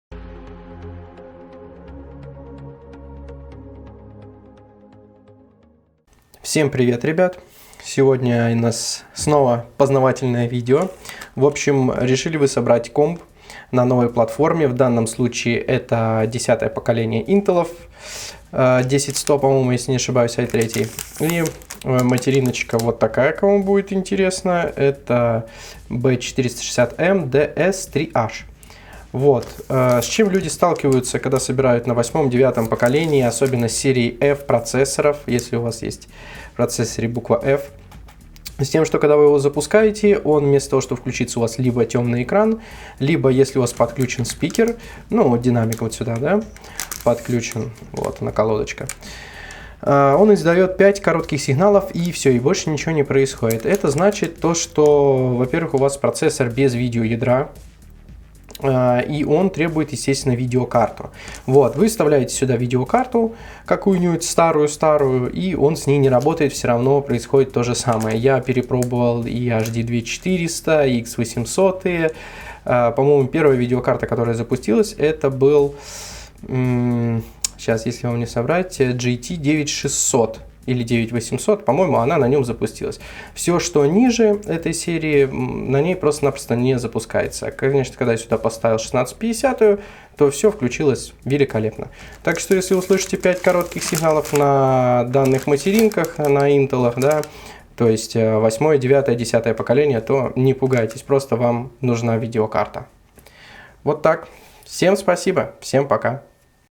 5 bipes curtos da BIOS 40260